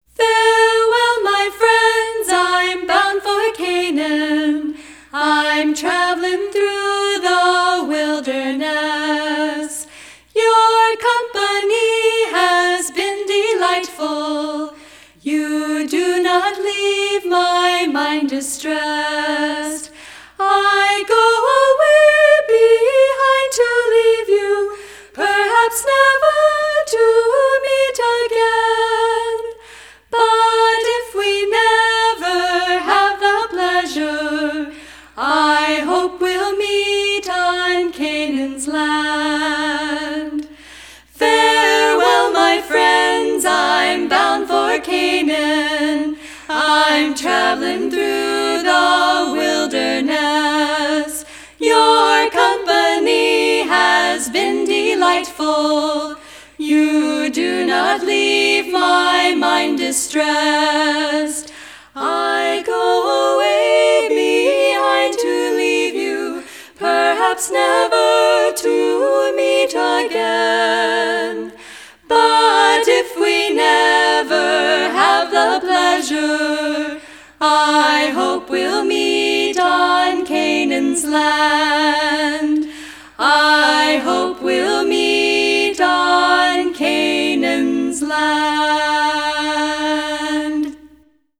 violin
flute, vocals
guitar, keyboard, vocals